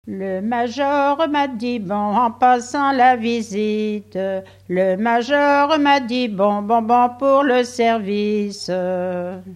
refrain de conscrits
Chants brefs - Conscription
Pièce musicale inédite